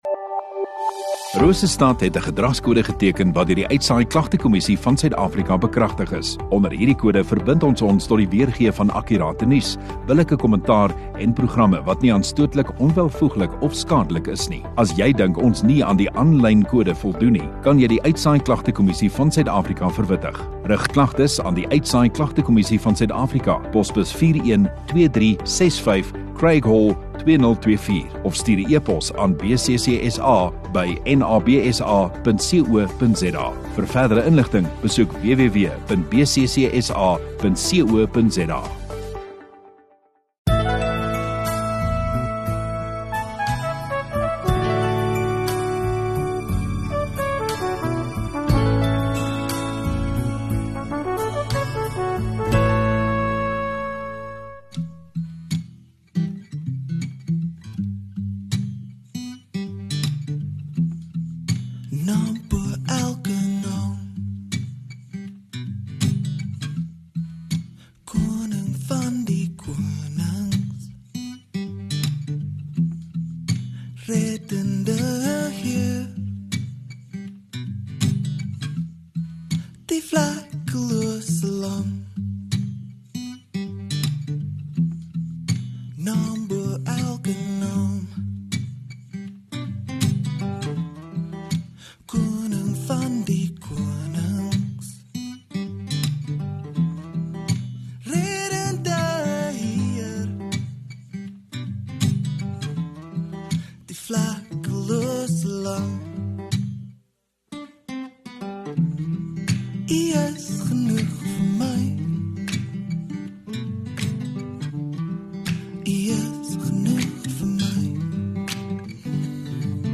22 Dec Sondagaand Erediens